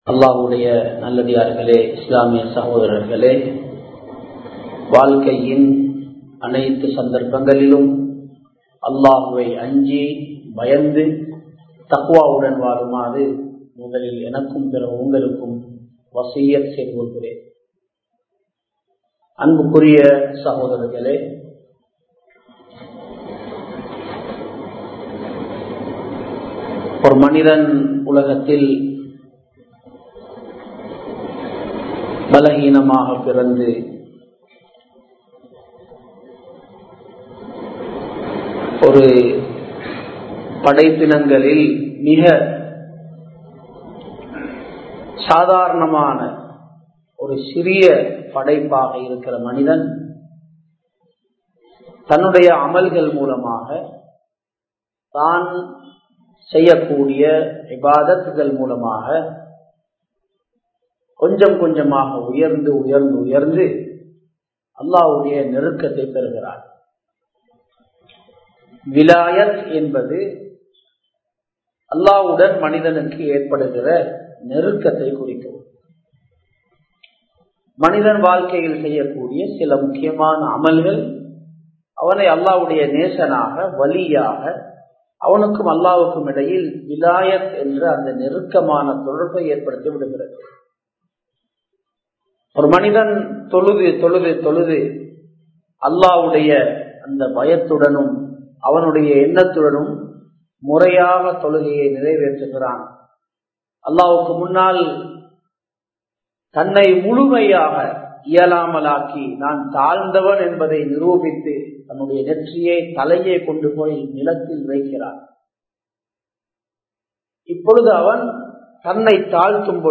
அல்லாஹ்வின் அன்பு வேண்டுமா? (Do you Need blessings of Allah?) | Audio Bayans | All Ceylon Muslim Youth Community | Addalaichenai
Muhiyadeen Jumua Masjith